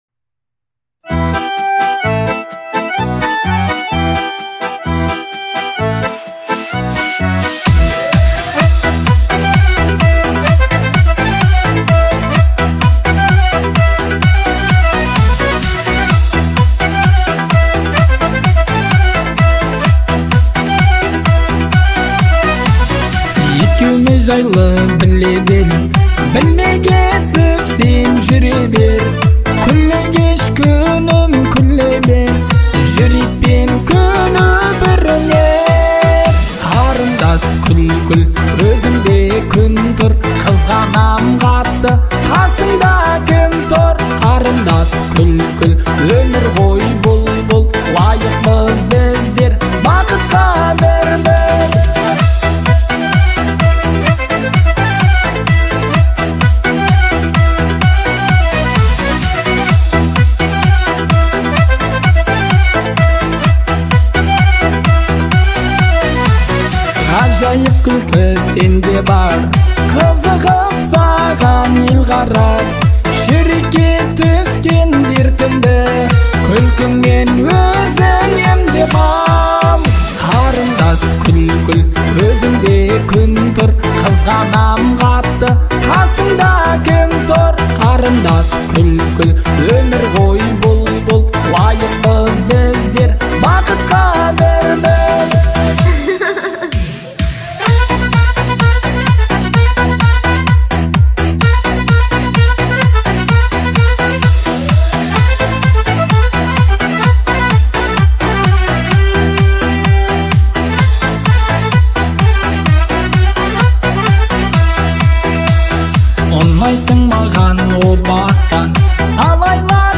казахские танцевальные песни